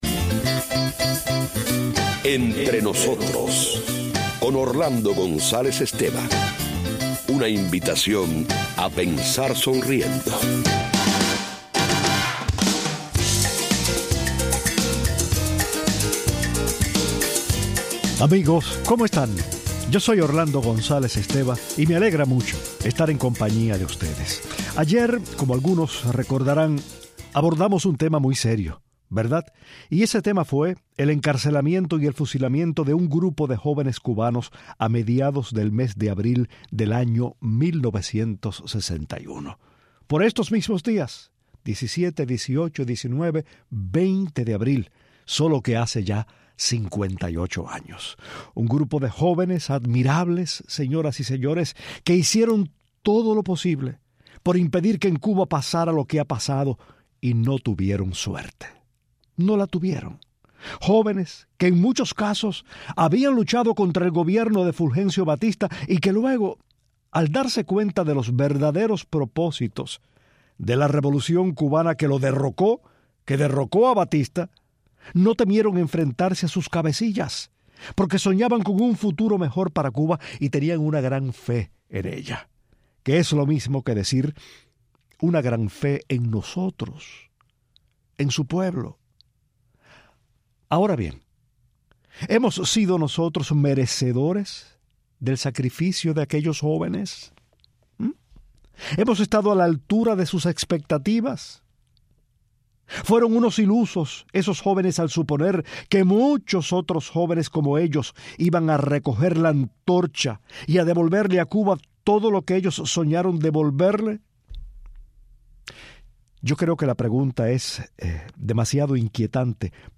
lee las cartas escritas por los jóvenes cubanos